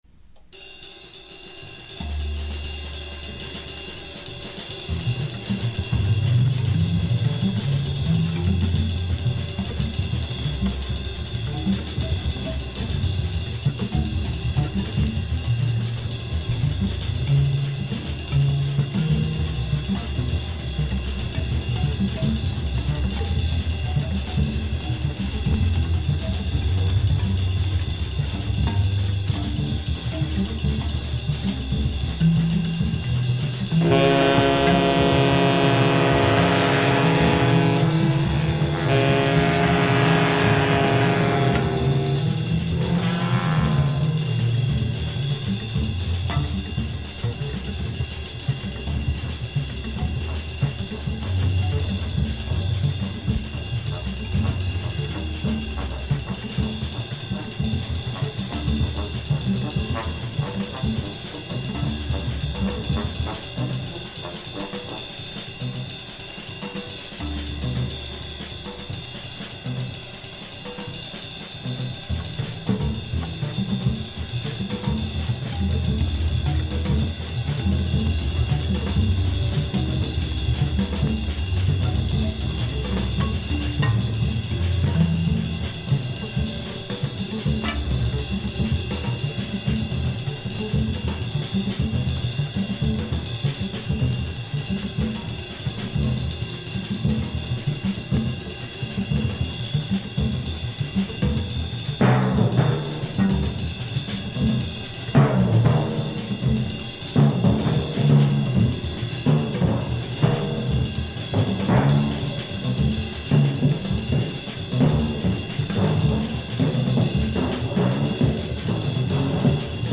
A cult avant garde / space jazz album !